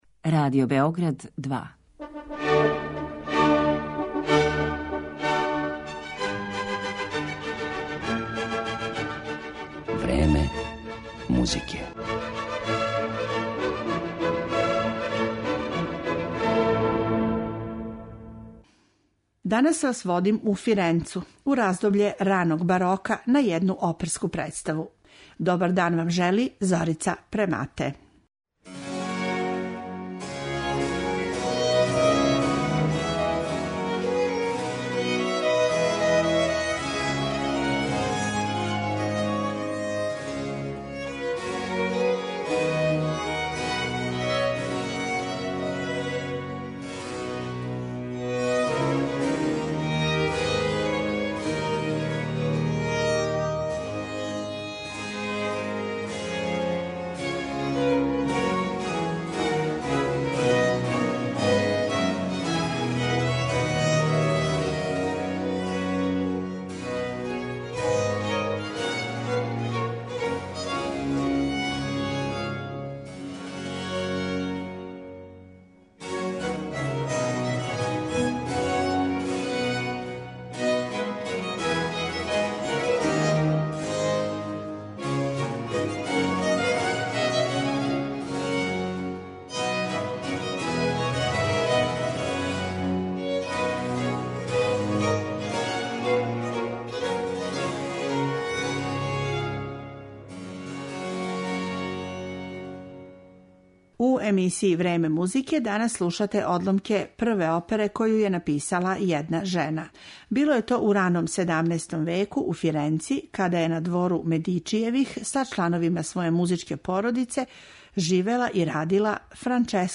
У емисији ћемо емитовати снимак који су остварили солисти Варшавске камерне опере и ансамбла за стару музику Musicae antique Collegium Varsoviense, који свира на копијама оригиналних инструмената тог доба.